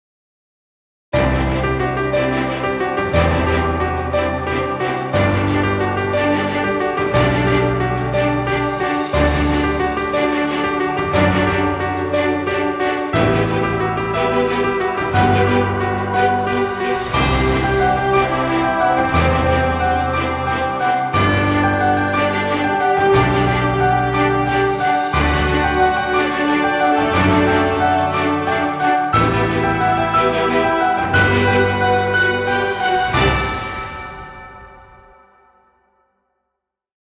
Epic music, exciting intro, or battle scenes.
Background Music Royalty Free.